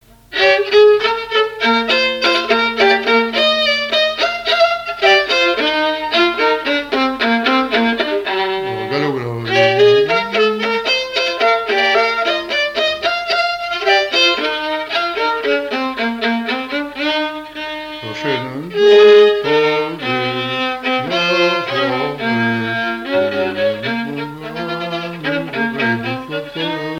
Mémoires et Patrimoines vivants - RaddO est une base de données d'archives iconographiques et sonores.
danse : quadrille : chaîne anglaise
Le quadrille et danses de salons au violon
Pièce musicale inédite